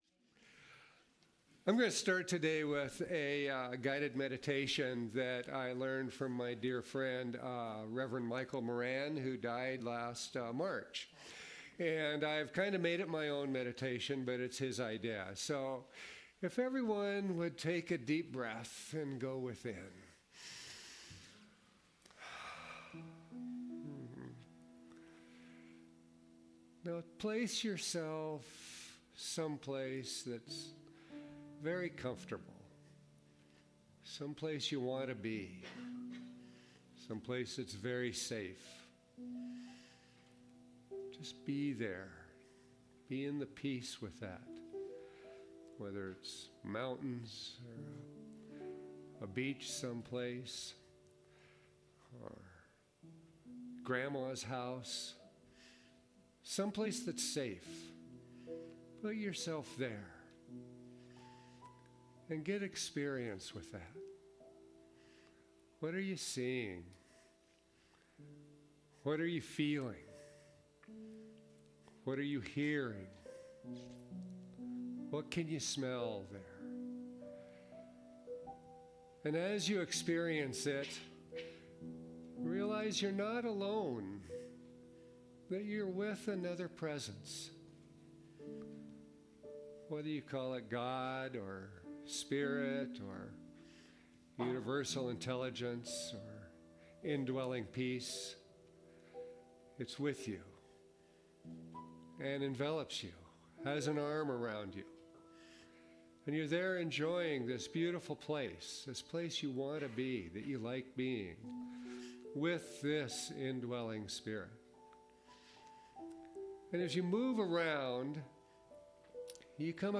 The audio recording (below the video clip) is an abbreviation of the service. It includes the Meditation, Lesson, and Featured Song.